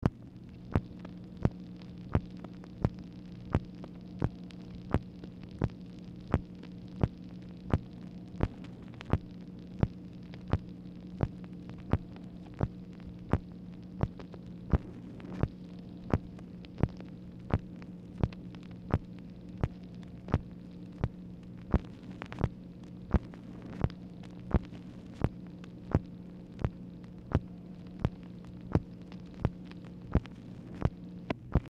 Telephone conversation # 7620, sound recording, MACHINE NOISE, 5/10/1965, time unknown | Discover LBJ
Format Dictation belt
Specific Item Type Telephone conversation